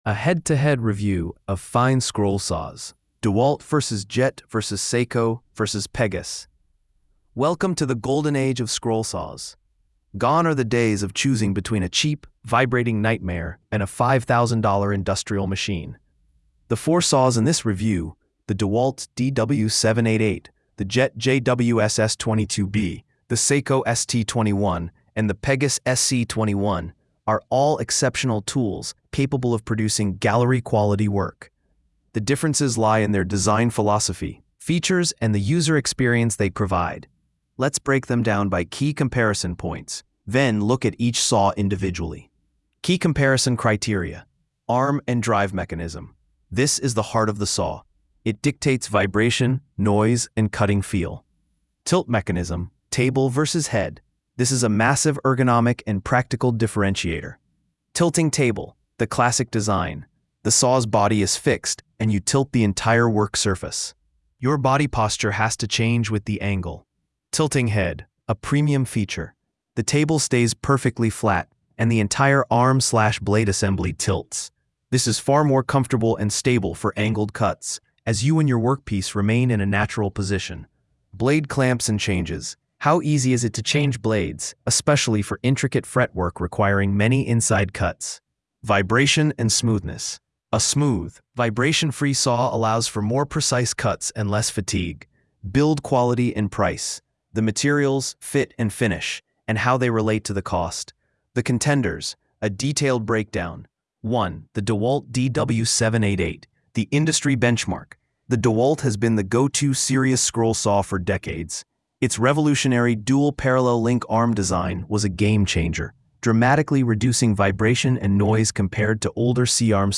I asked Google Studio AI to do a comparison review of the DeWalt, Jet, Seyco, and Pegas scroll saws.